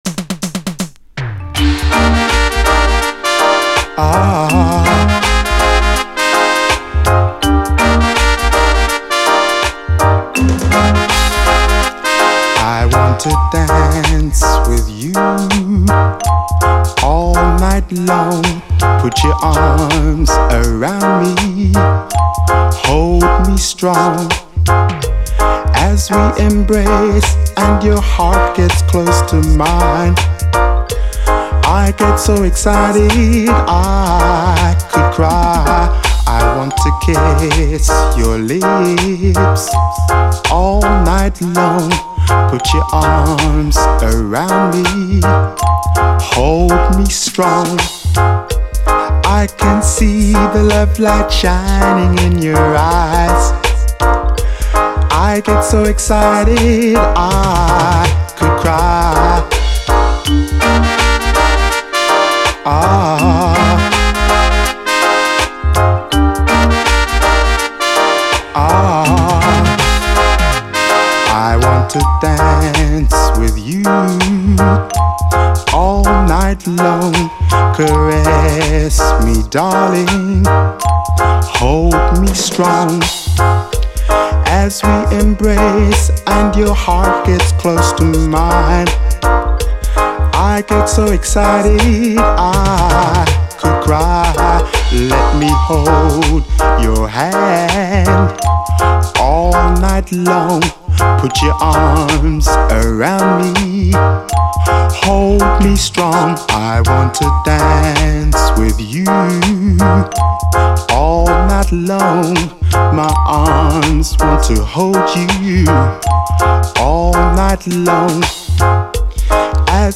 REGGAE
シンセ・ブラスのチープな音がむしろ新鮮な、最高胸キュンUKラヴァーズです！